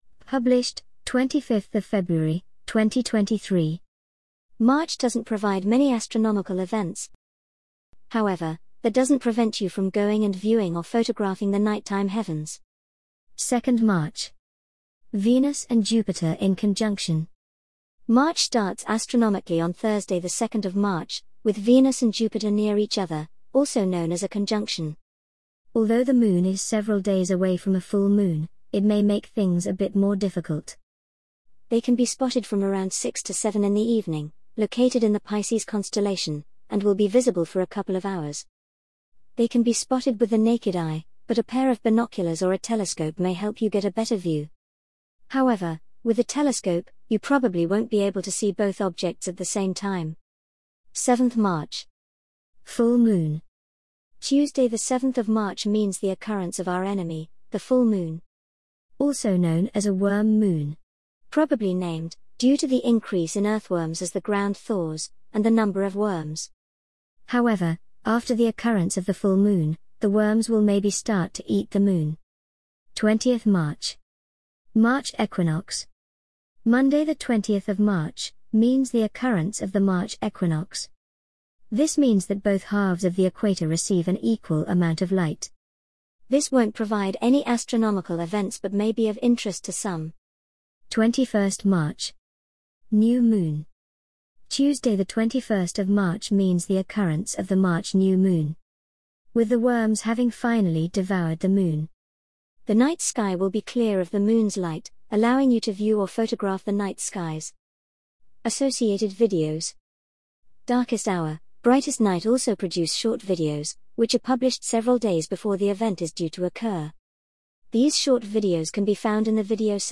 An audio reading of the Realm of Darkness January 2023 Article